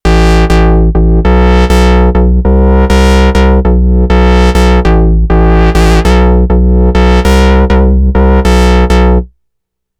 Novation Peak – Klangbeispiele
novation_peak_test__sync_lead.mp3